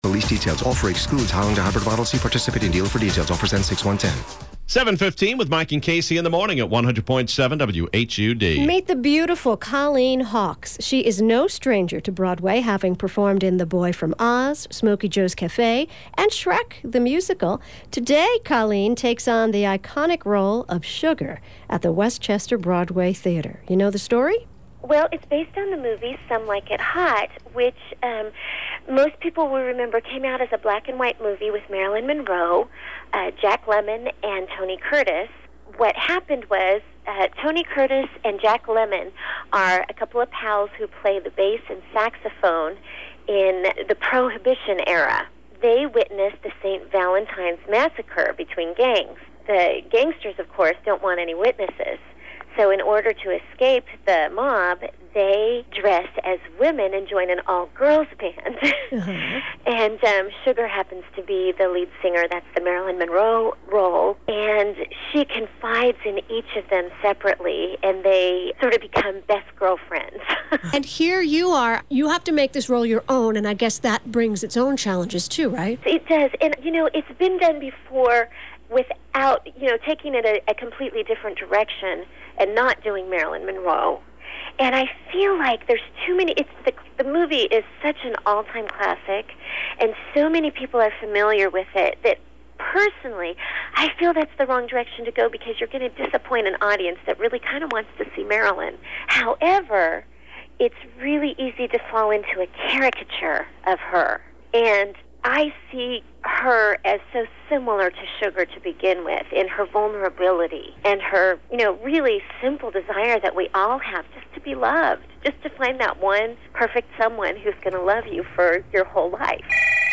SugarInterview.mp3